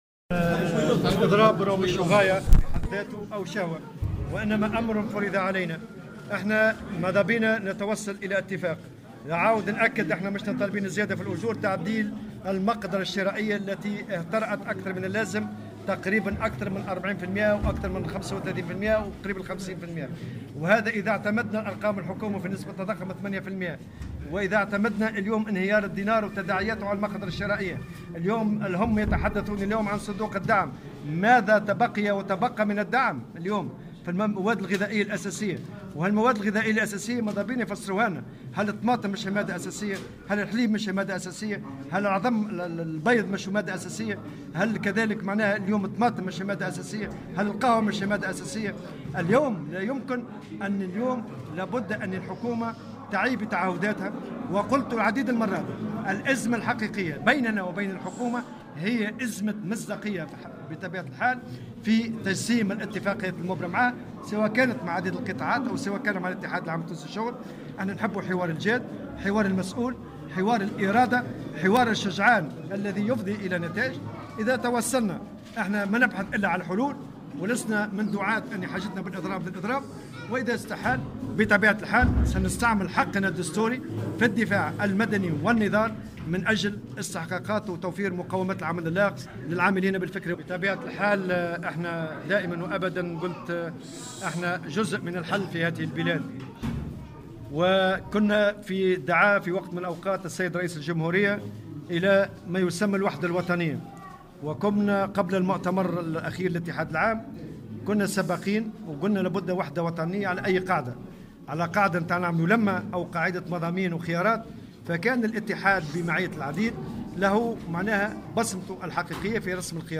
تصريح مقتضب